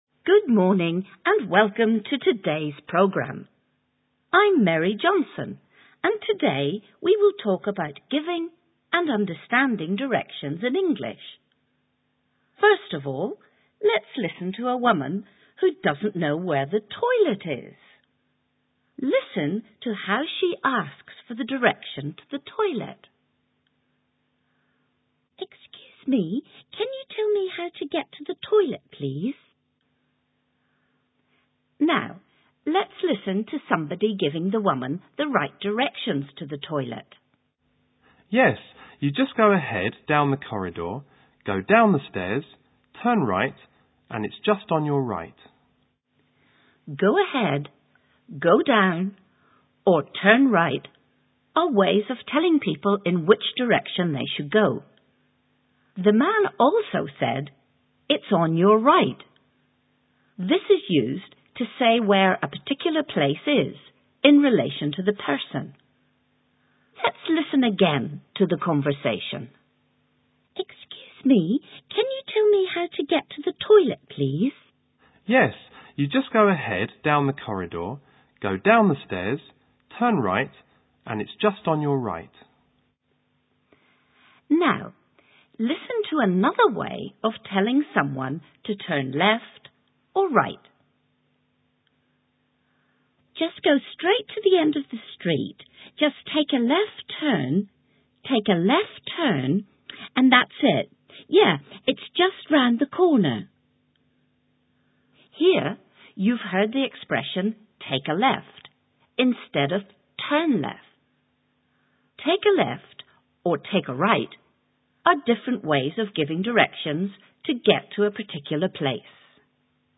Eight minute lesson: listen to this audio clip in which some language used to give directions is explained very clearly.
radio_programme.mp3